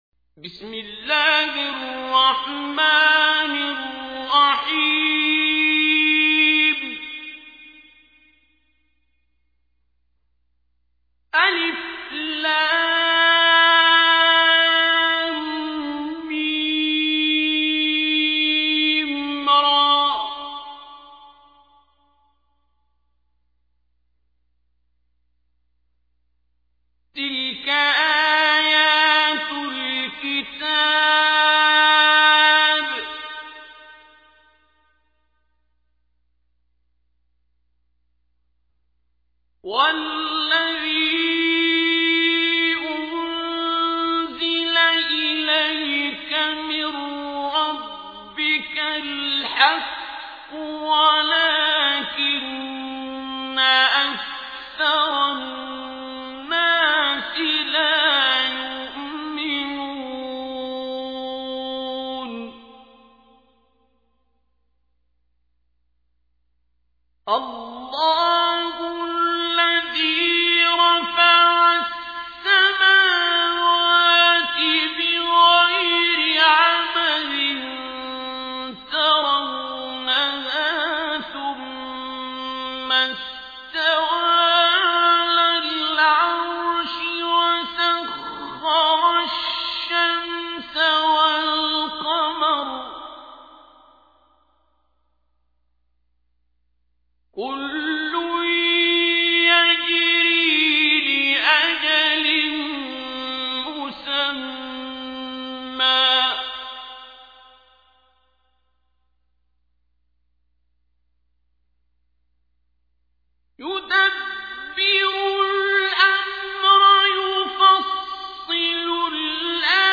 تحميل : 13. سورة الرعد / القارئ عبد الباسط عبد الصمد / القرآن الكريم / موقع يا حسين